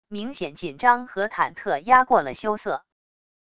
明显紧张和忐忑压过了羞涩wav音频